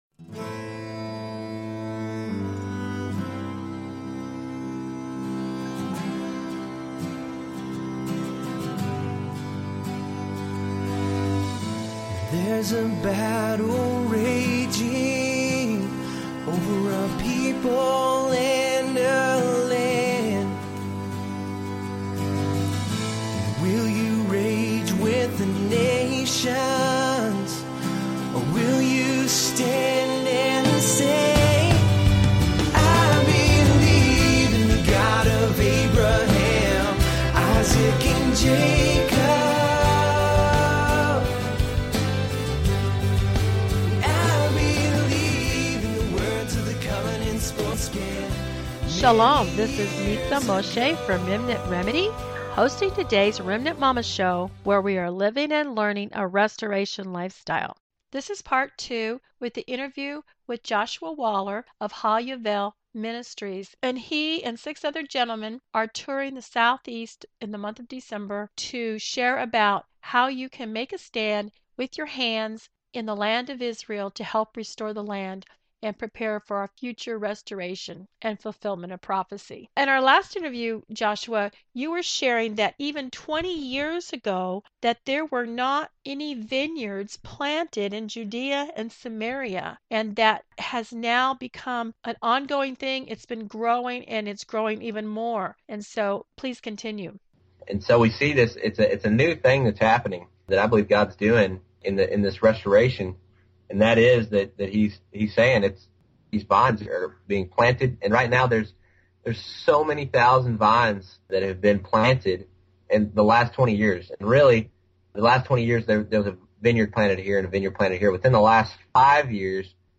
while on Zion’s Sake Tour